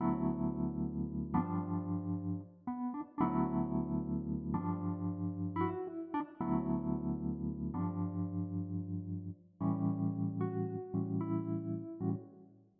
罗德和弦
Tag: 105 bpm Hip Hop Loops Piano Loops 3.08 MB wav Key : Unknown